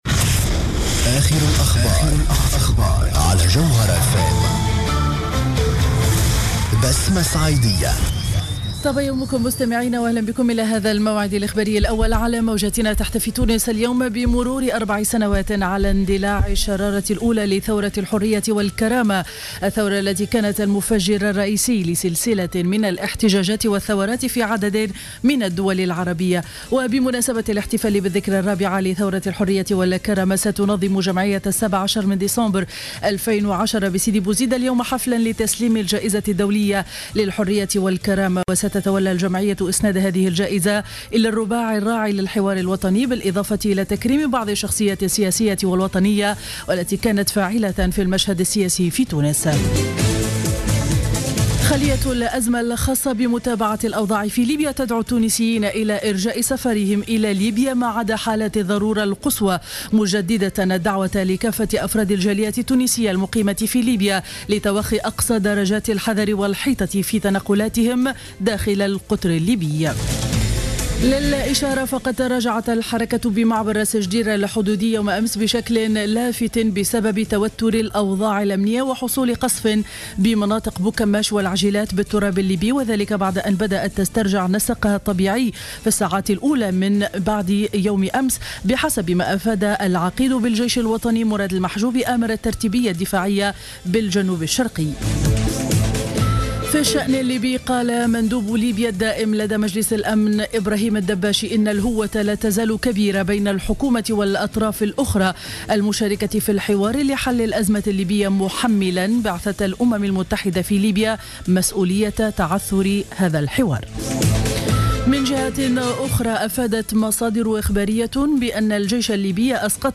نشرة اخبار السابعة صباحا ليوم الإربعاء 17 ديسمبر 2014